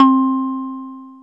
JUP.8 C4   3.wav